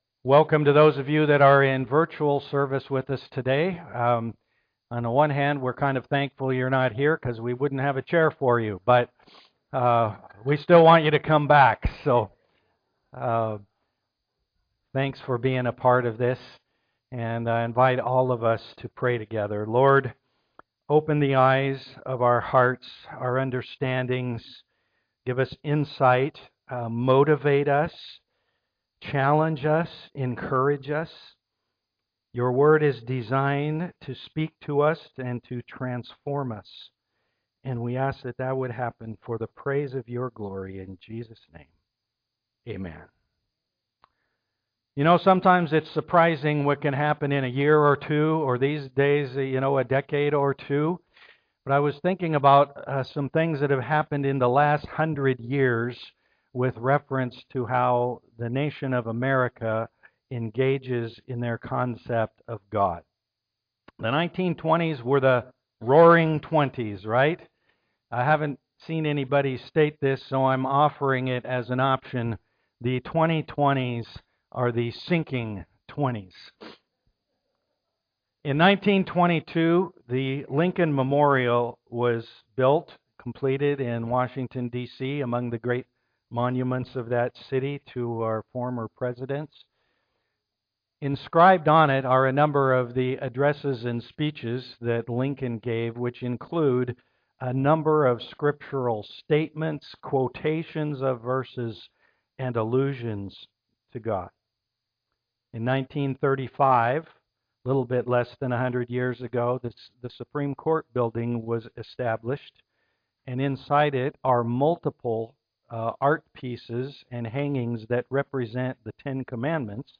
Jeremiah Service Type: am worship It's Independence Day weekend.